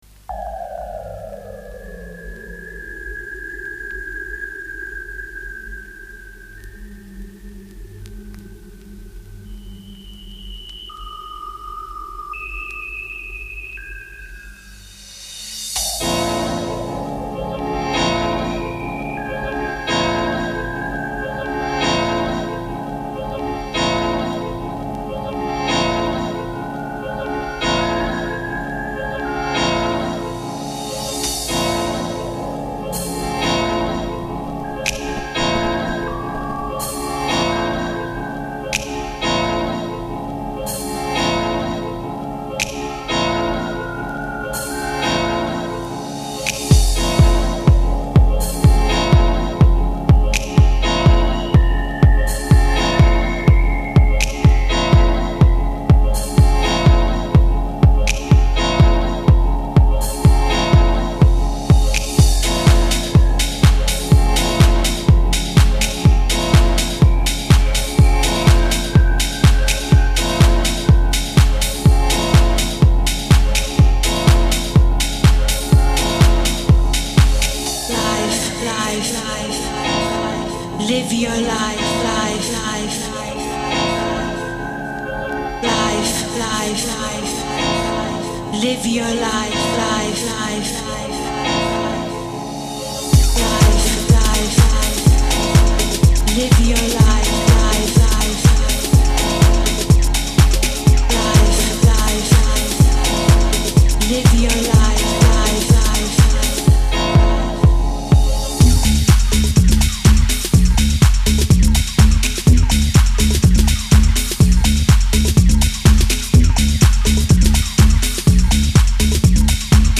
una sessione mixata
il mixer era un giocattolo